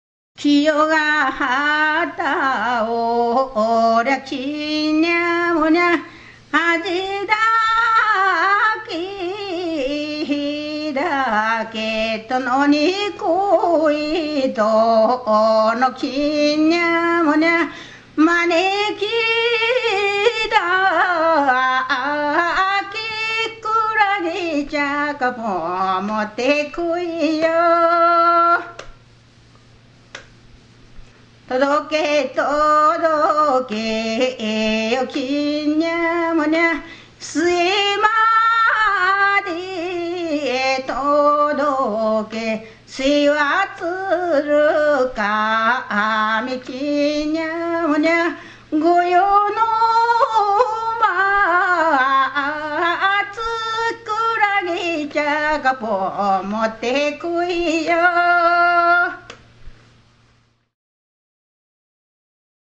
歌い手　女性・大正3年（1914）生
隠岐地方を代表する座敷歌の一つであり、海士町の歌とされている。これは最近では、フォークダンスで用いられることもあるが、元々は宴席の余興でうたいながら踊ることが多い。
ところで、ここに挙げた最初のものが「キンニャモニャ」独自の詞章で、囃し言葉を除けば、近世民謡調の七七七五調であることが分かる。